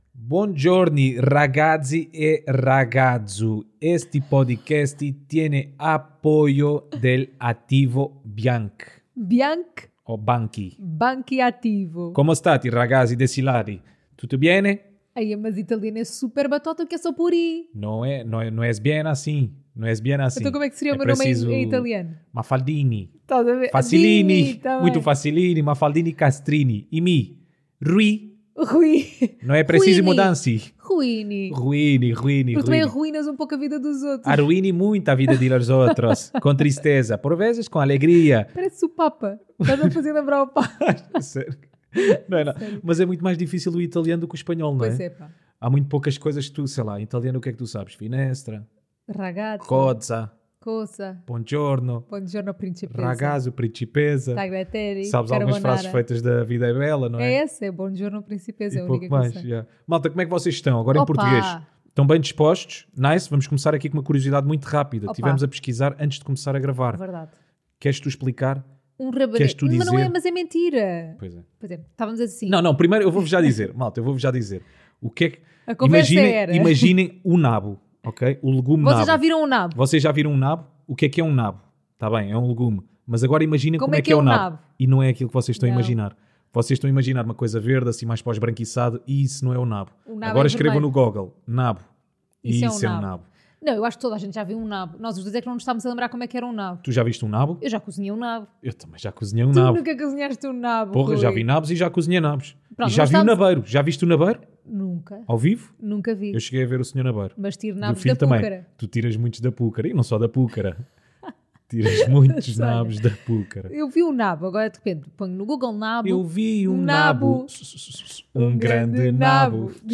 Um casal à conversa sobre temas pouco relevantes para uns e muito pouco relevantes para outros.